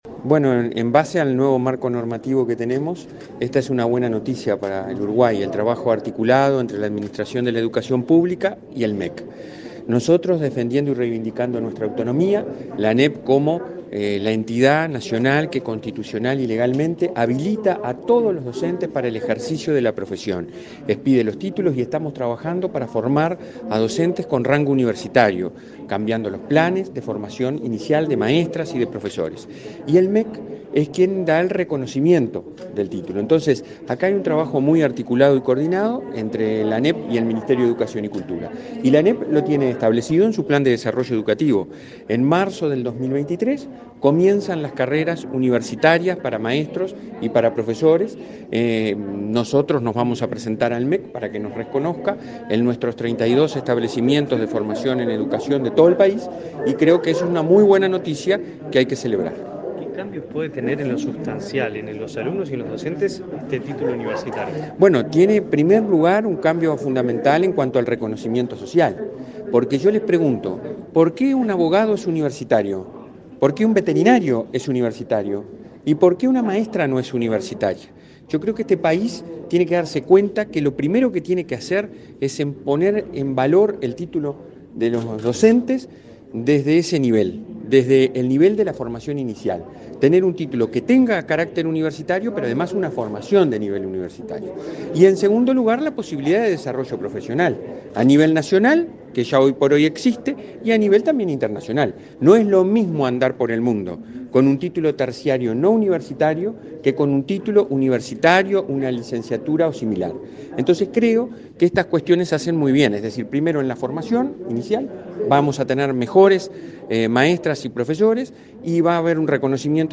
Declaraciones a la prensa del presidente de la ANEP